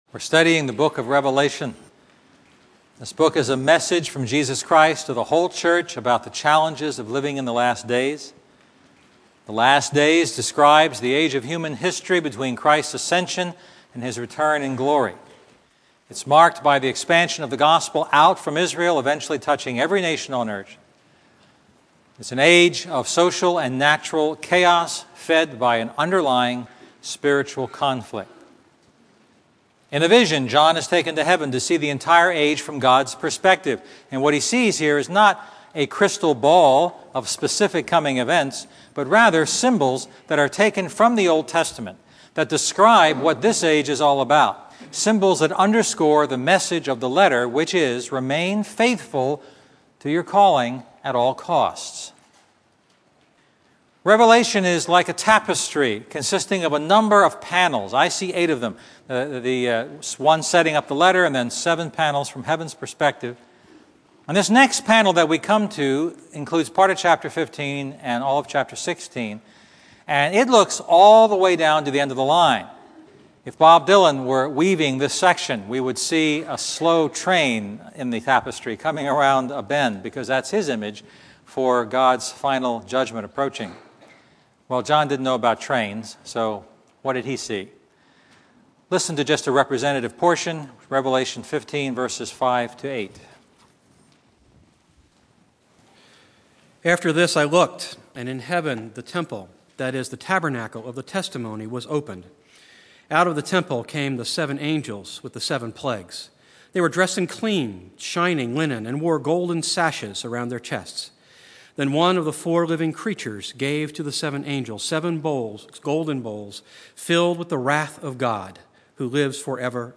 Expository